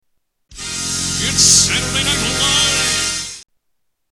TV Theme Songs